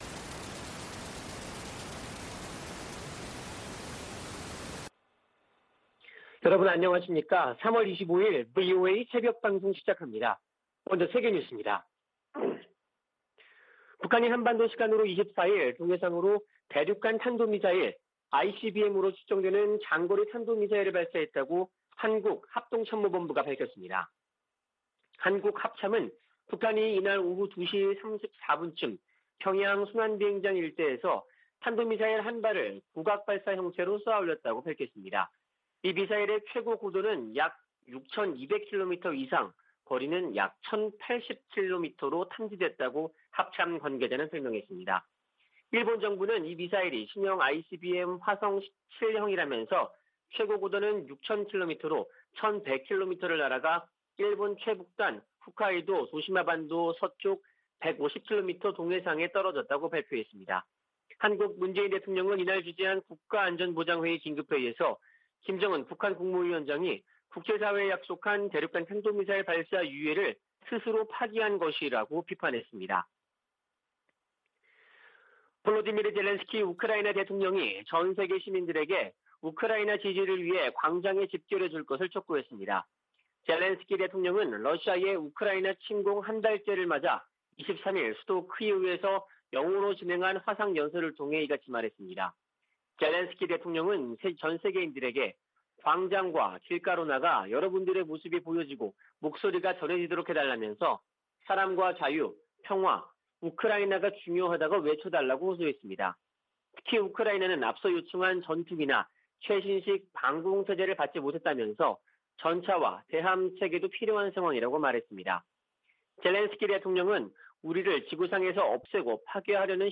VOA 한국어 '출발 뉴스 쇼', 2022년 3월 25일 방송입니다. 북한이 24일 대륙간탄도미사일(ICBM)으로 추정되는 미사일을 발사했습니다.